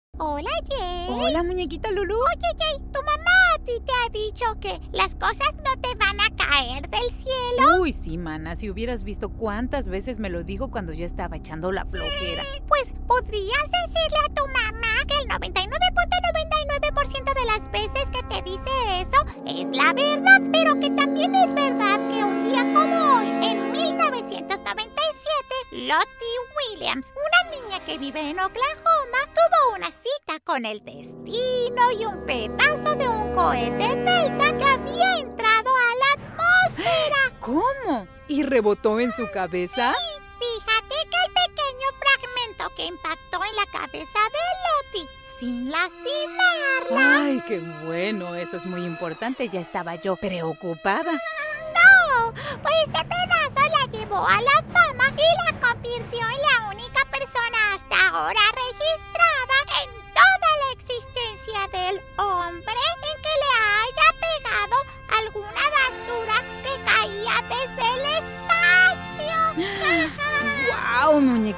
Spanish (Mexican)
Animation
I have a clear voice and ability to do various tones, accents, and emotions to convey material properly.